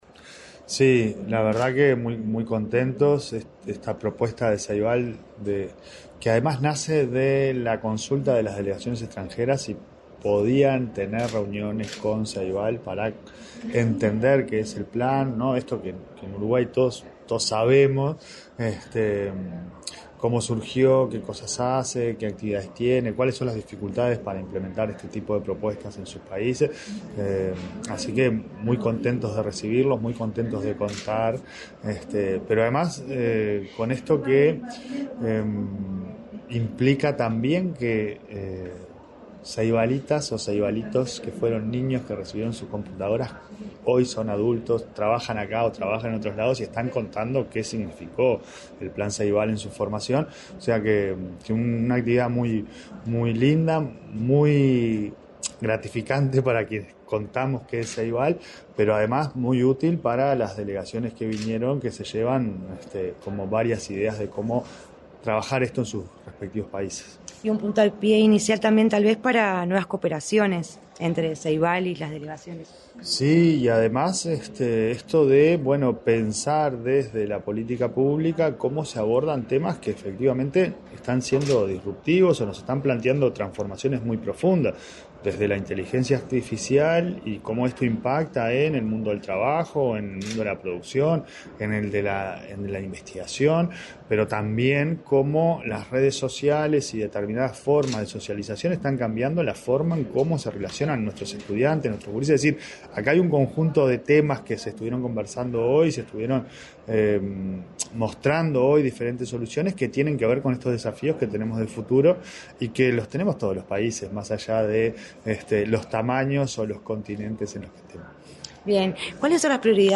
Declaraciones del designado presidente de la ANEP, Pablo Caggiani
Declaraciones del designado presidente de la ANEP, Pablo Caggiani 02/03/2025 Compartir Facebook X Copiar enlace WhatsApp LinkedIn El designado presidente de la Administración Nacional de Educación Pública (ANEP), Pablo Caggiani, dialogó con la Presidencia de la República, durante la recorrida que visitantes extranjeros realizaron a las instalaciones de Ceibal.